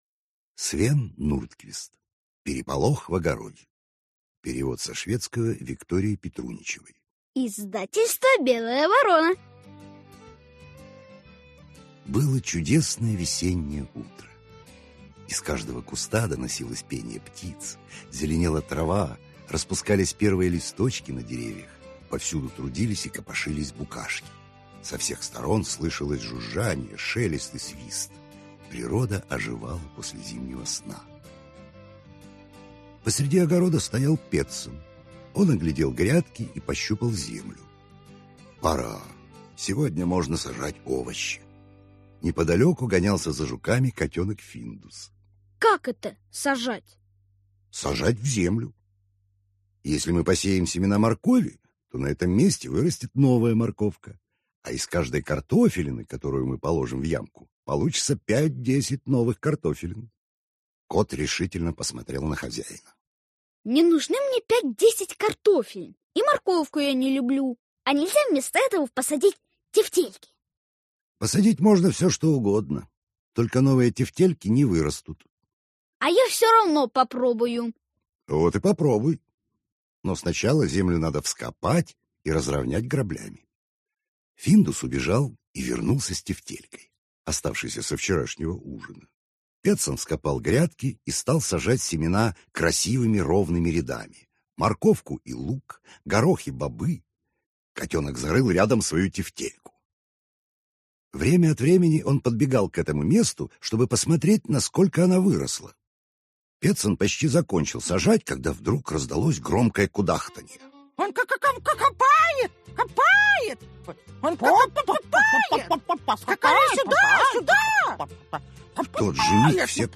Аудиокнига Переполох в огороде | Библиотека аудиокниг